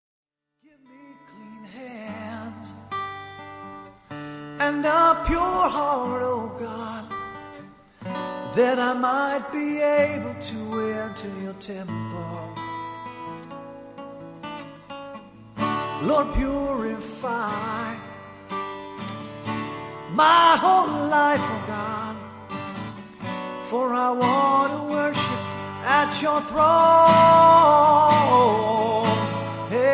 worship music